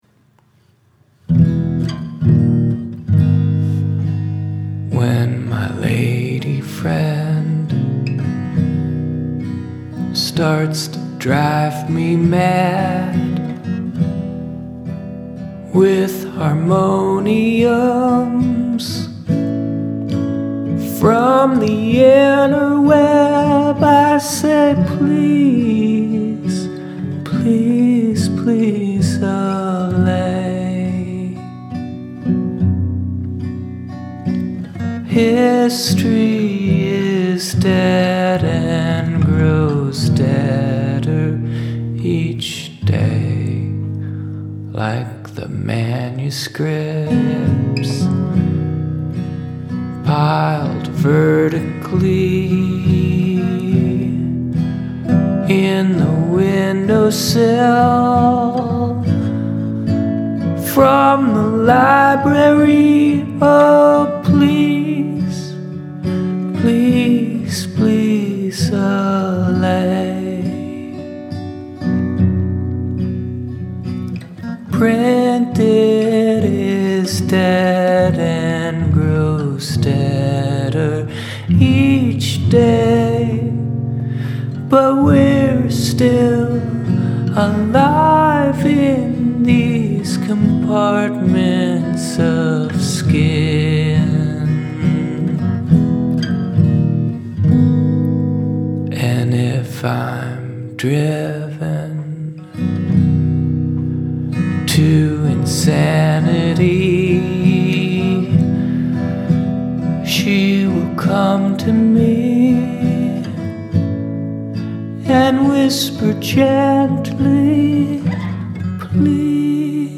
i have the guitar tuned down right now, and a capo on the first fret. so not sure where this actually is, but here are the chord shapes:
intro: F, E, Am
verse: Am, C, Fm, Bflat